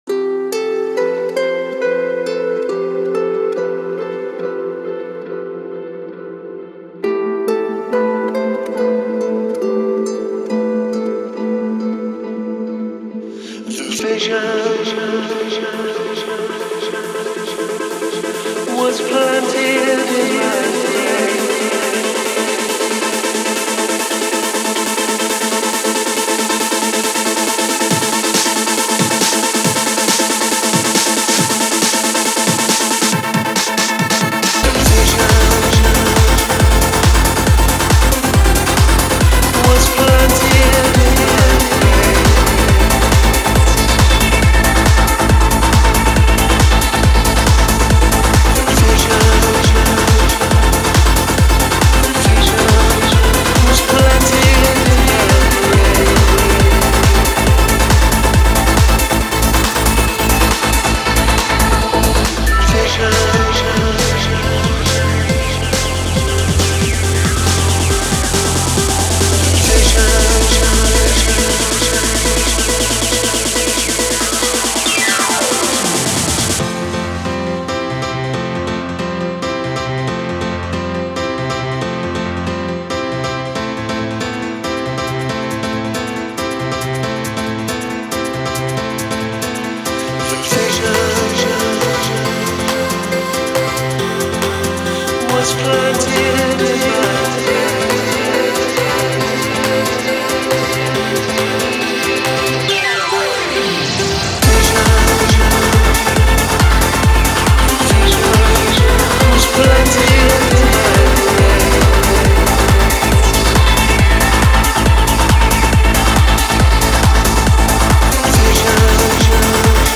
BPM138
Audio QualityPerfect (High Quality)
Comments[TRANSCENDING TRANCE]